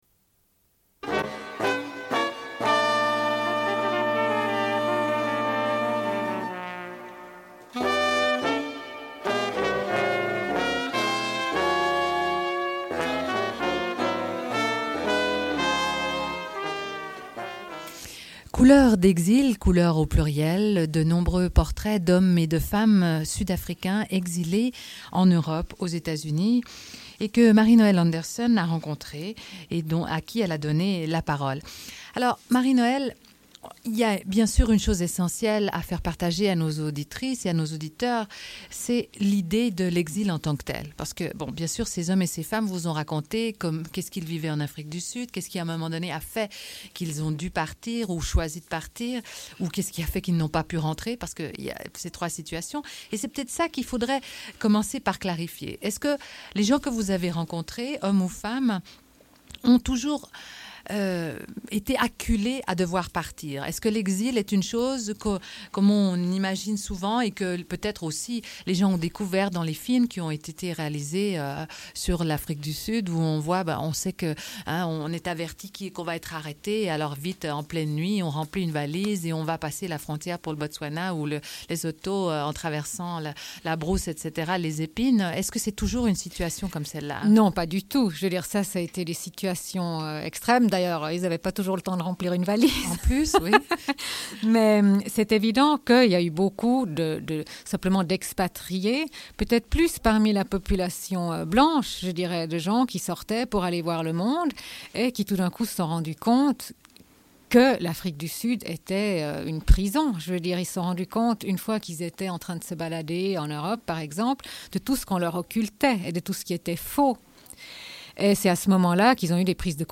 Une cassette audio, face B28:34